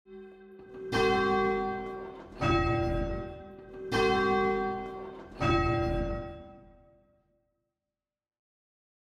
Major 6th Intervals
A major 6th interval is the inverse of a minor 3rd.